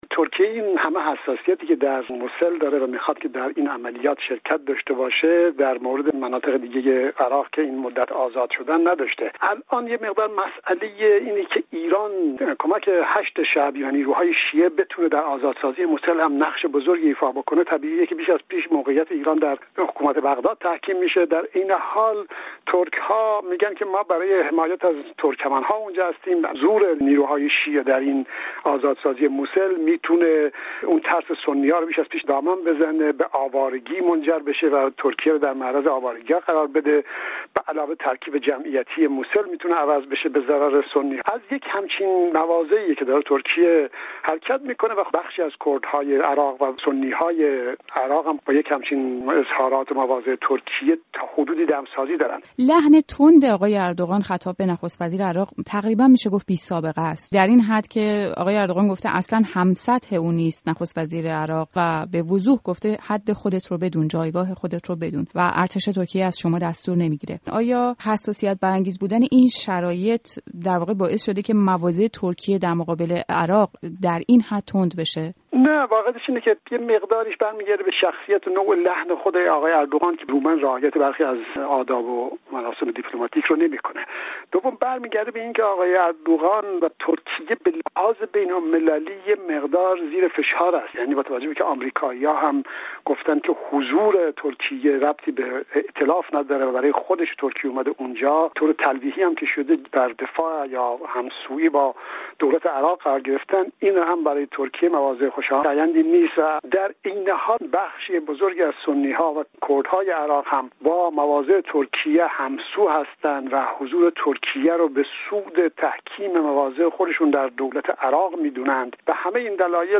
گفت‌وگوی رادیو فردا